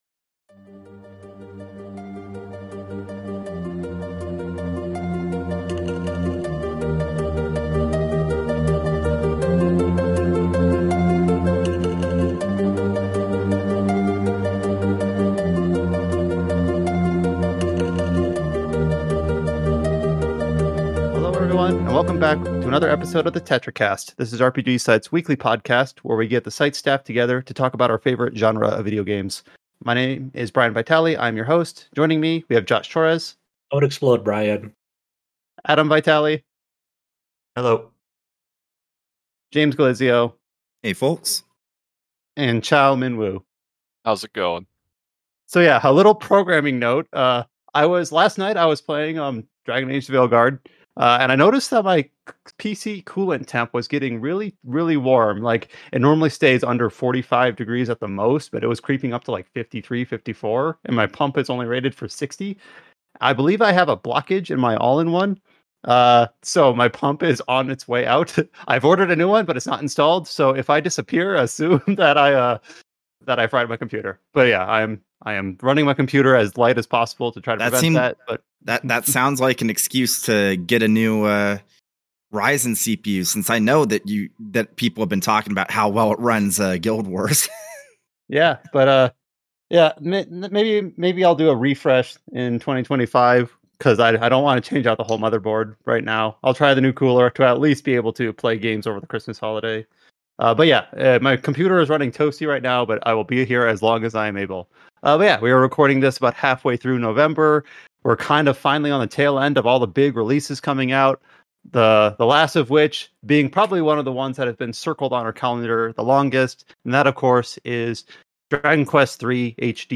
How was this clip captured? Enjoy 2 hours of lighthearted conversation...and if lock those doors!